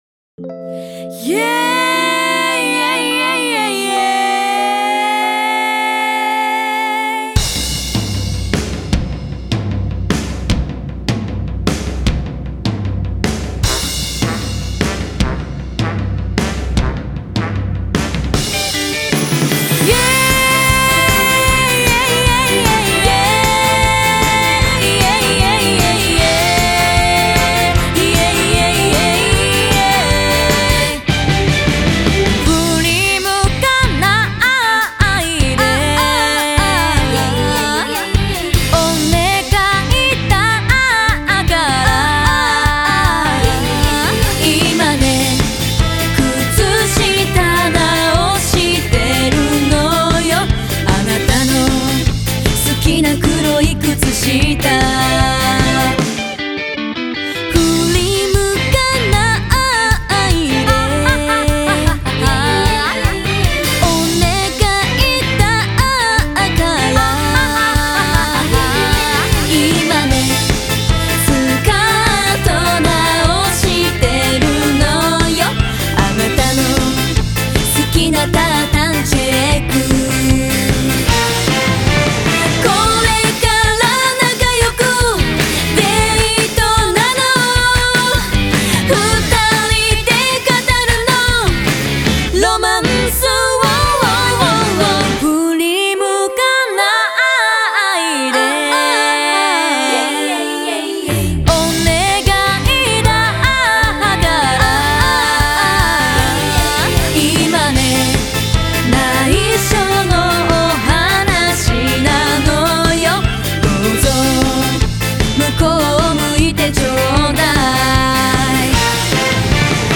аранжировка направлена на современный лад.